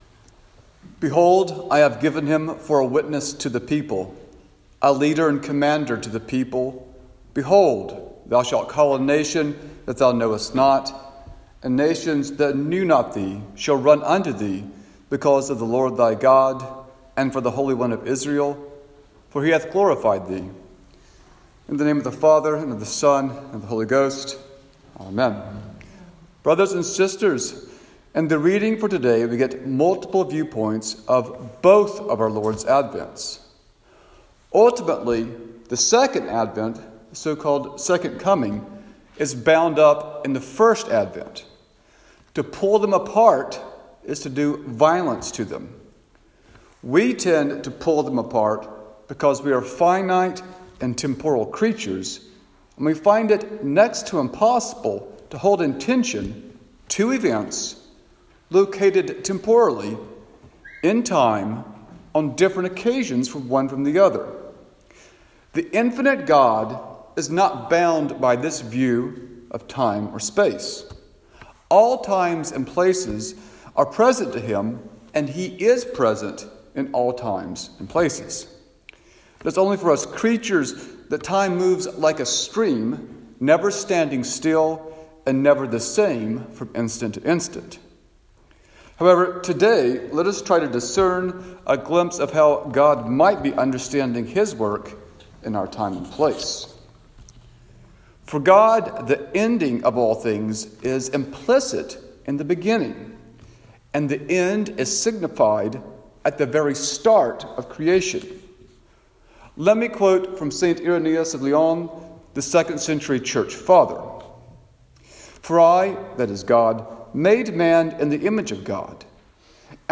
Sermon for Advent 2 - 2021